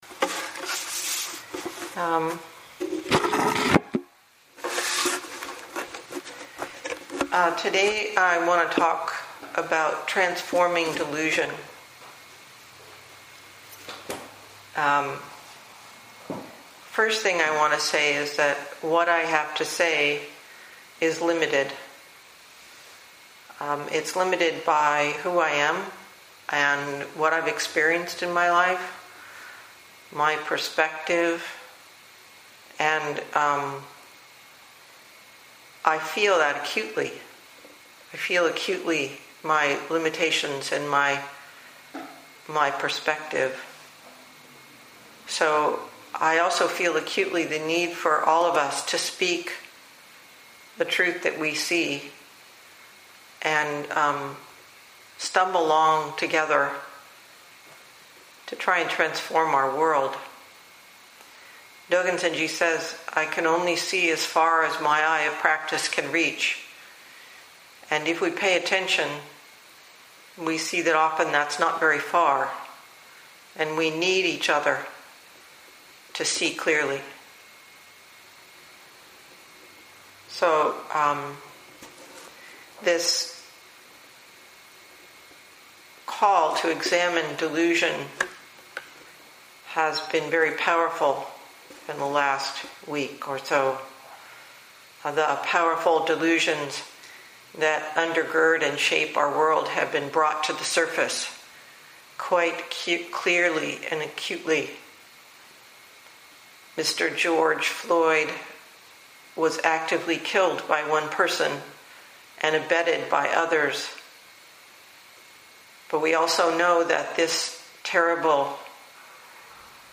2020 in Dharma Talks